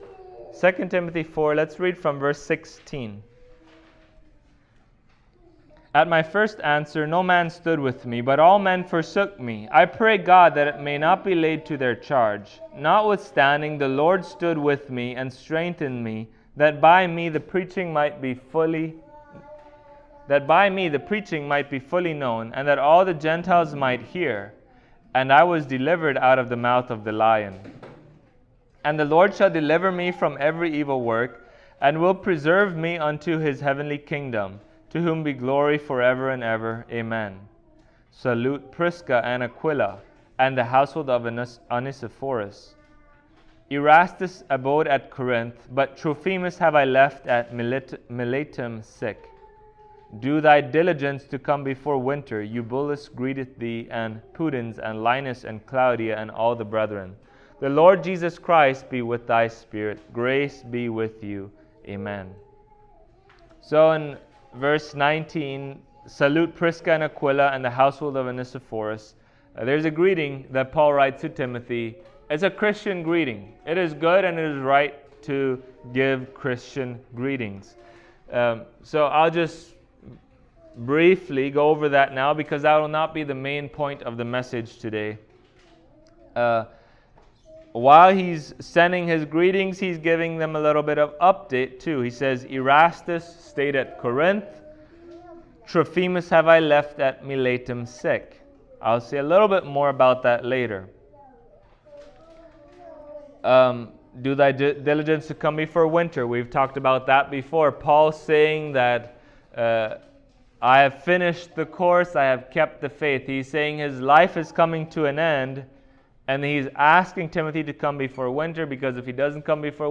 Passage: 2 Timothy 4:17-22 Service Type: Sunday Morning